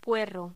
Locución: Puerro
voz
Sonidos: Voz humana